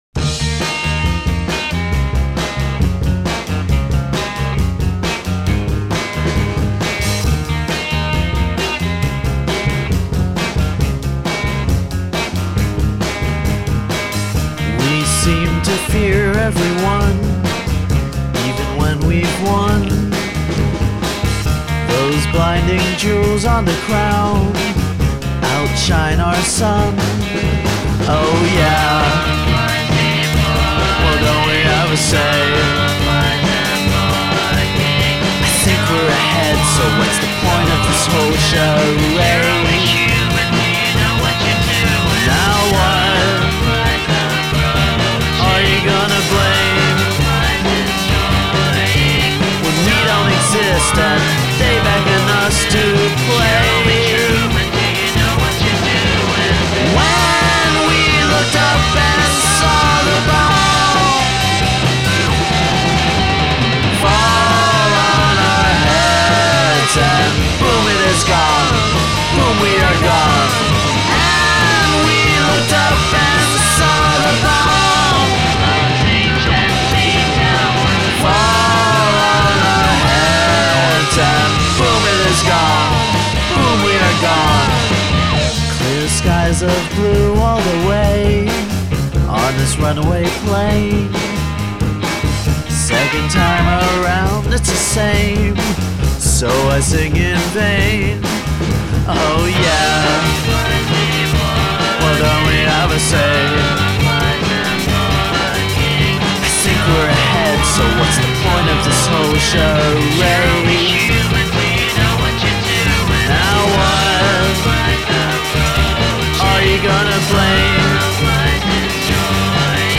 indie rock band
Bonus tracks and remixes